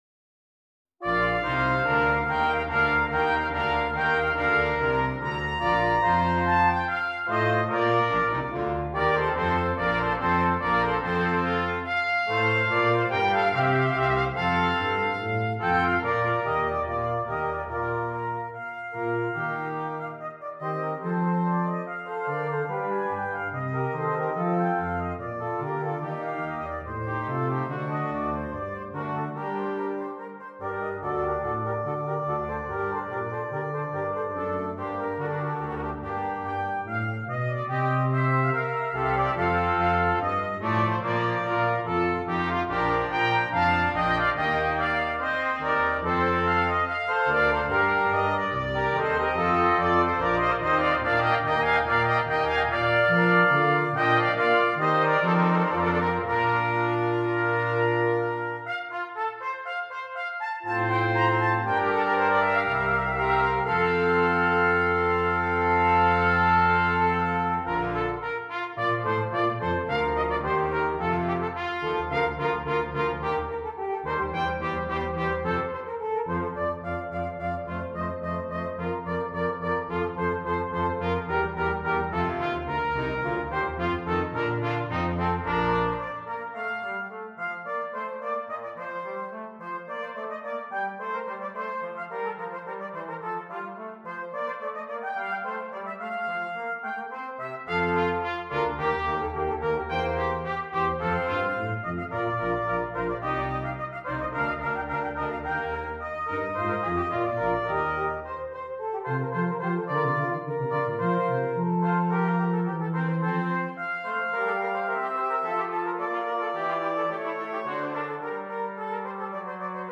• Brass Quintet